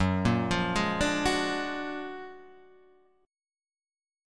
F#+M9 chord